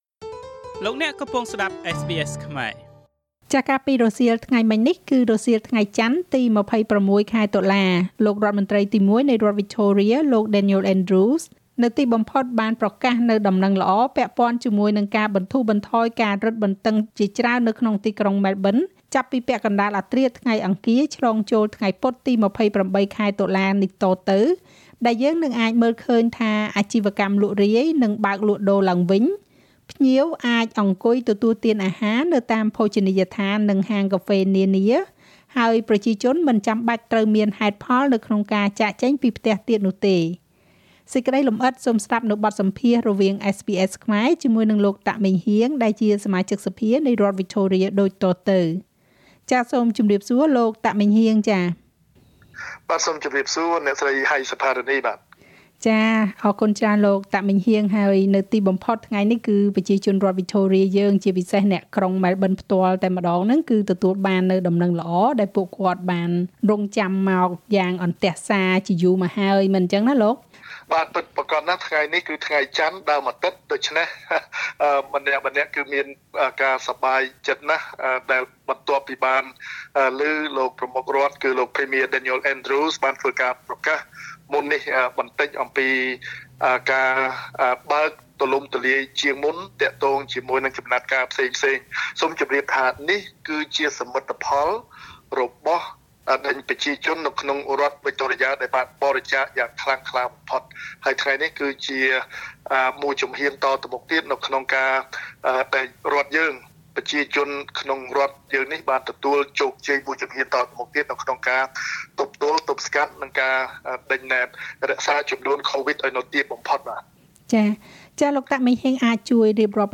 កាលពីថ្ងៃរសៀលនេះ រដ្ឋមន្រ្តីទីមួយនៃរដ្ឋវិចថូរៀ លោក Daniel Andrews នៅទីបំផុត បានប្រកាសពីការបន្ធូរបន្ថយការរឹតបន្តឹងបន្ថែមទៀតនៅក្នុងទីក្រុងម៉ែលប៊ន ចាប់ពីពាក់កណ្តាលអធ្រាត្រថ្ងៃអង្គារ ឆ្លងចូលថ្ងៃពុធ ទី២៨ខែតុលា តទៅ ដែលយើងនឹងមើលឃើញថា អាជីវកម្មលក់រាយនឹងបើកលក់ដូរឡើងវិញ ភ្ញៀវអាចអង្គុយទទួលទានអាហារនៅតាមភោជនីយដ្ឋាន និងហាងកាហ្វេនានា ហើយប្រជាជនមិនចាំបាច់ត្រូវមានហេតុផលក្នុងការចាកចេញពីផ្ទះទៀតនោះទេ។ សេចក្តីលំអិត សូមស្តាប់បទសម្ភាសន៍រវាងSBSខ្មែរ ជាមួយនឹងលោក តាក ម៉េងហ៊ាង សមាជិកសភានៃរដ្ឋវិចថូរៀដូចតទៅ។
Victorian MP Meng Heang Tak at SBS studio in Melbourne Source: SBS Khmer